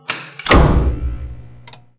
switch_6on.wav